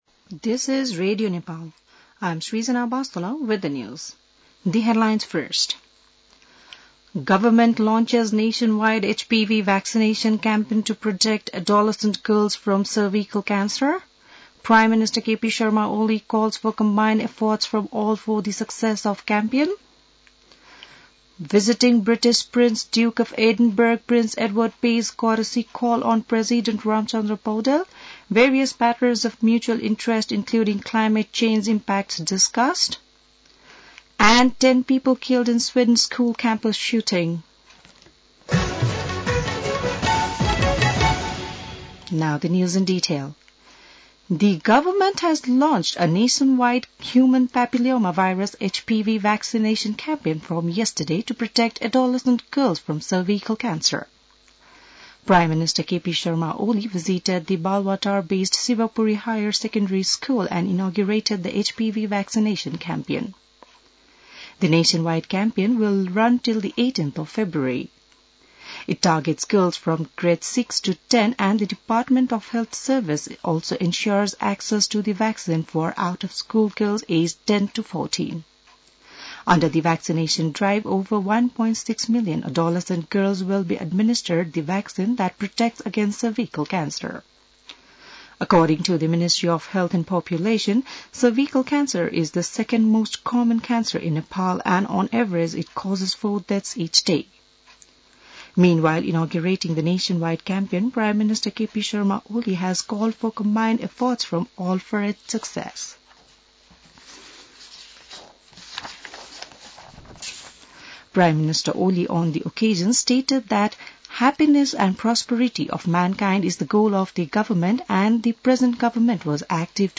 बिहान ८ बजेको अङ्ग्रेजी समाचार : २४ माघ , २०८१